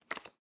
three_card.m4a